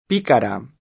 p&kara], col pl. pícaras [p&karaS]; italianizz. picara [p&kara] (pl.